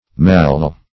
malleal - definition of malleal - synonyms, pronunciation, spelling from Free Dictionary Search Result for " malleal" : The Collaborative International Dictionary of English v.0.48: Malleal \Mal"le*al\, a. (Anat.)
malleal.mp3